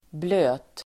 Uttal: [blö:t]